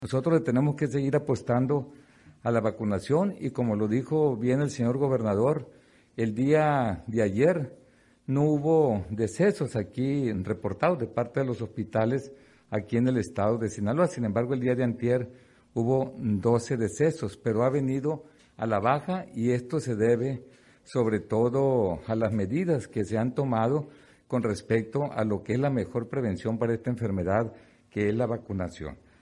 Al encabezar su primera conferencia de prensa semanal, denominada “La Semanera”, el gobernador Rubén Rocha Moya reiteró que su gobierno se distinguirá por su alto sentido social y humanista, y como prueba de ello recordó que sus primeras reuniones de trabajo fueron los encuentros que sostuvo con los colectivos de desplazados forzosos, y de búsqueda de personas desaparecidas, en cumplimiento al compromiso que hizo en el sentido de que lo primero que haría como gobernador, sería recibirlos para escucharlos y diseñar una política de Estado en esta materia.
A su vez, el secretario de Salud, Héctor Melesio Cuen Ojeda, se refirió a la pandemia del COVID, y precisó que la mejor estrategia para prevenirla es la vacunación.